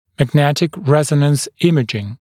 [mæg’netɪk ‘rezənəns ‘ɪmɪʤɪŋ] [мэг’нэтик ‘рэзэнэнс ‘имиджин] магнитно-резонансная томография